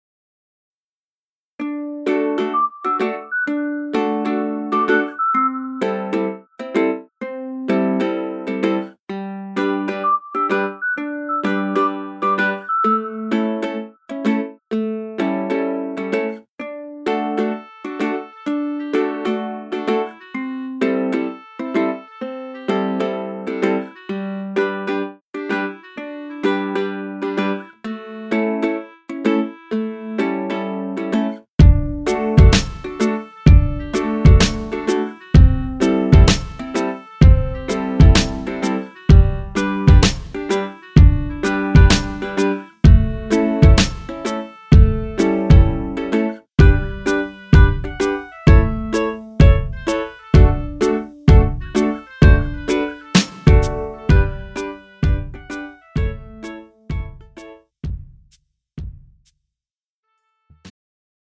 음정 -1키 3:12
장르 가요 구분 Pro MR